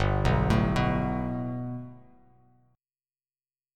Gadd9 chord